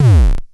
Index of /90_sSampleCDs/300 Drum Machines/Klone Dual-Percussion-Synthesiser/KLONE FILT NW8